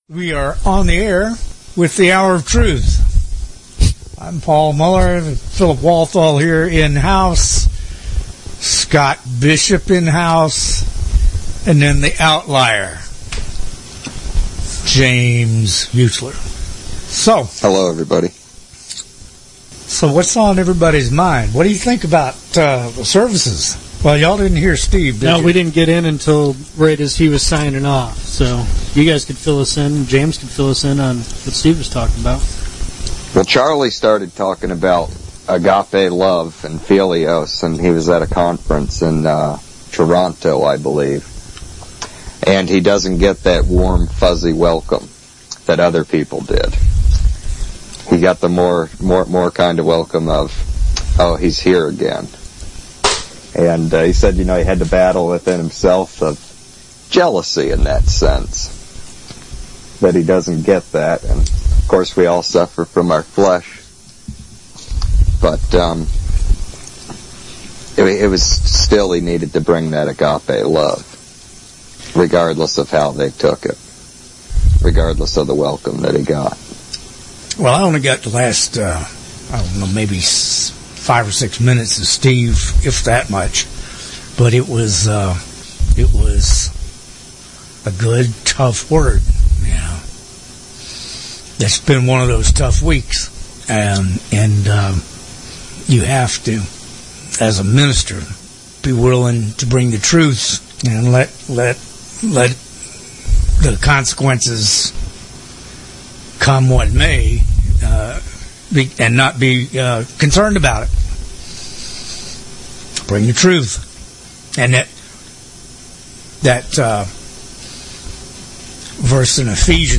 Sunday Hour of Truth Service 02/22/2015 | The Fishermen Ministry